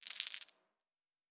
Dice Shake 2.wav